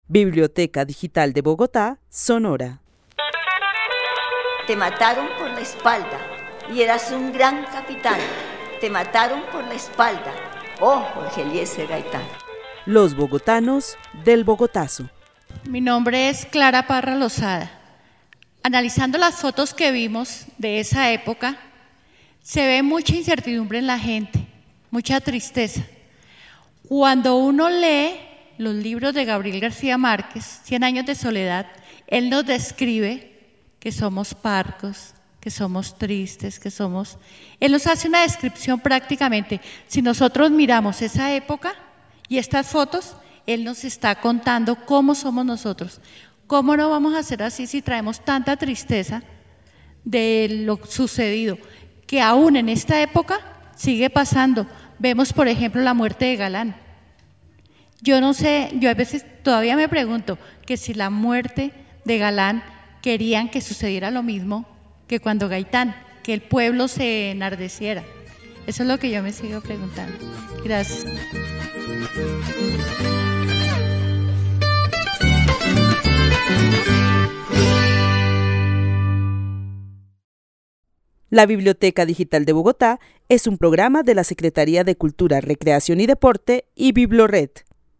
Narración oral sobre la violencia en Bogotá desde la década de 1950, partiendo del análisis de las fotografías de Sady González y los libros de Gabriel García Márquez. El testimonio fue grabado en el marco de la actividad "Los bogotanos del Bogotazo" con el club de adultos mayores de la Biblioteca Carlos E. Restrepo.